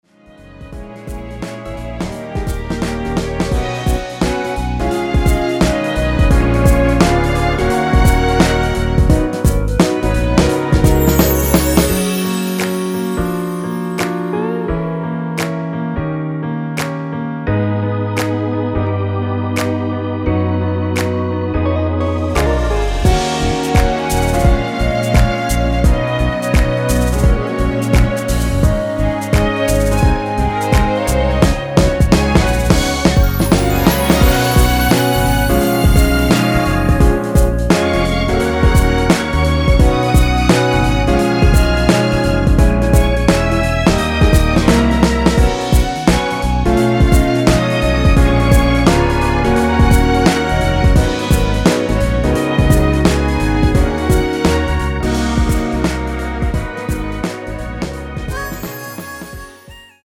엔딩이 페이드 아웃이라서 노래하기 편하게 엔딩을 만들어 놓았으니 코러스 MR 미리듣기 확인하여주세요!
원키에서(-2)내린 (1절+후렴)으로 진행되는 멜로디 포함된 MR입니다.
Gb
노래방에서 노래를 부르실때 노래 부분에 가이드 멜로디가 따라 나와서
앞부분30초, 뒷부분30초씩 편집해서 올려 드리고 있습니다.